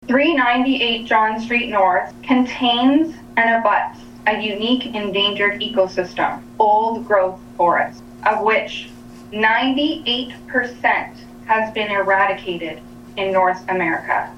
addressing Arnprior Town Council Monday evening (January 27th).